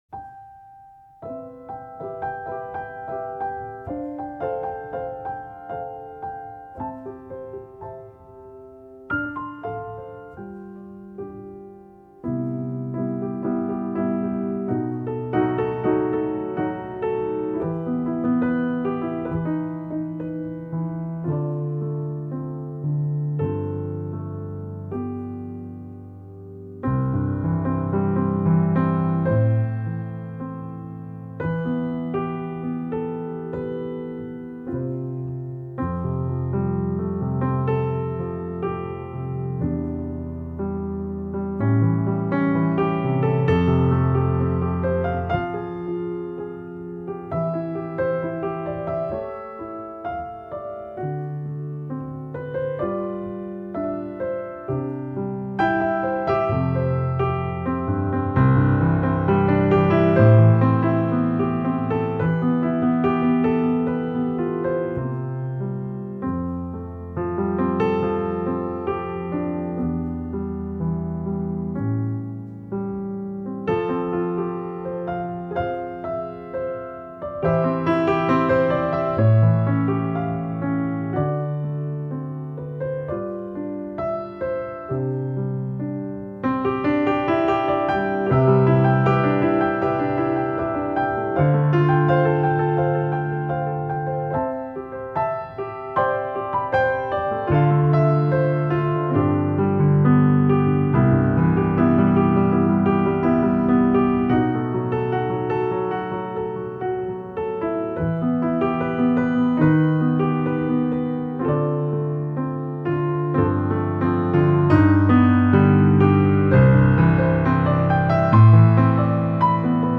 篇，愿温暖流畅的琴声带给大家美好的一天。